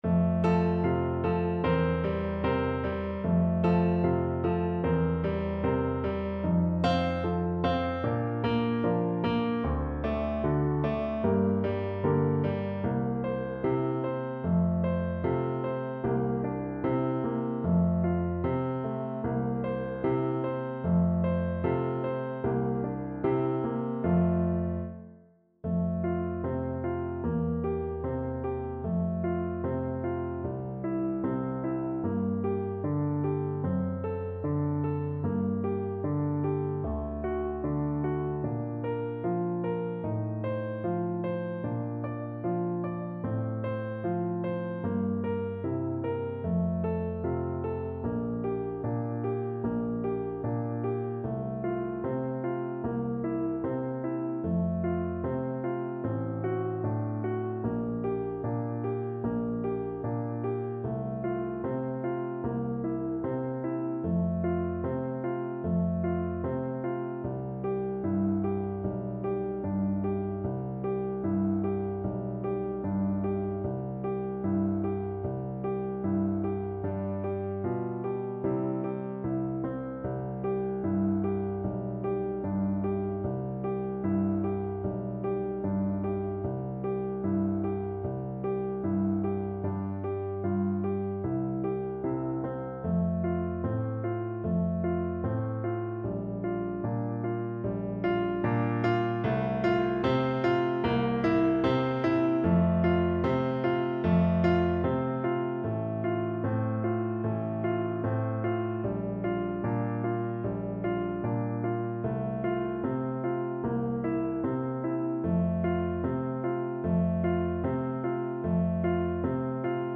Clarinet
2/2 (View more 2/2 Music)
Allegro moderato (View more music marked Allegro)
F major (Sounding Pitch) G major (Clarinet in Bb) (View more F major Music for Clarinet )
Classical (View more Classical Clarinet Music)